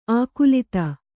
♪ ākulita